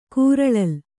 ♪ kūraḷal